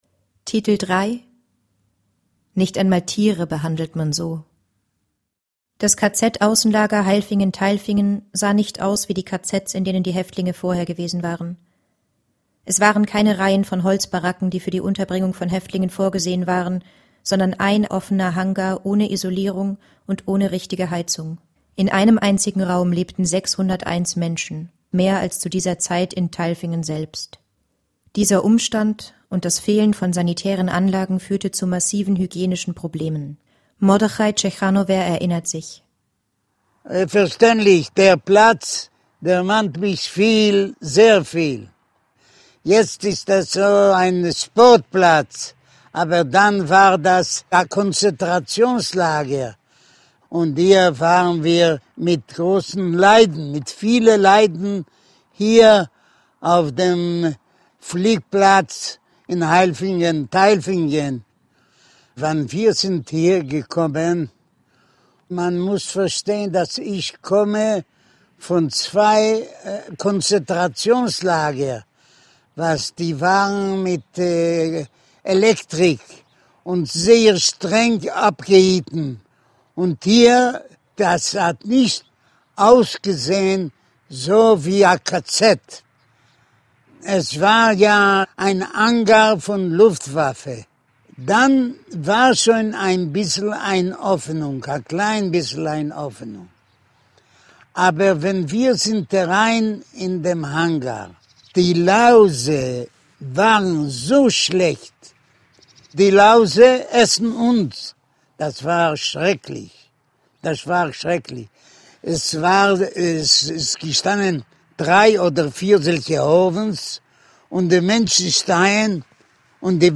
Audioguide Titel 3
niedrige Audioqualität